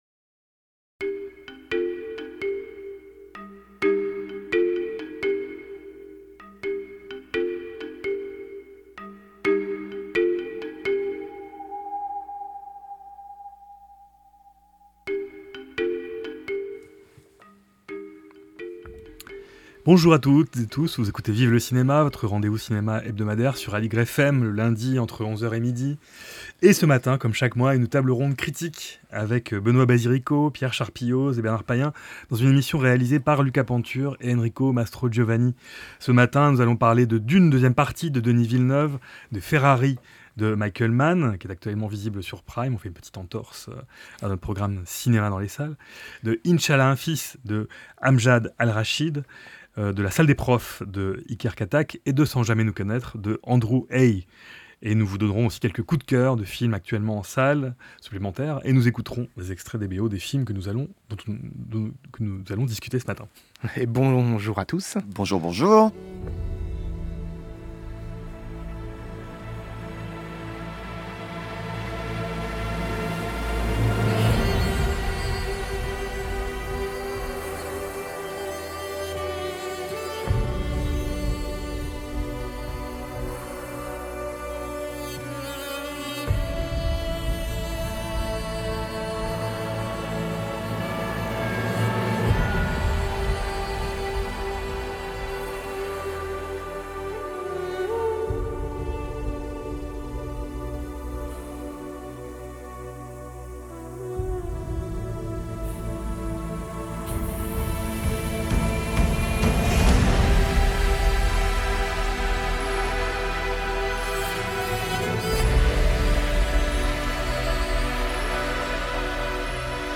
Table ronde critique autour des films :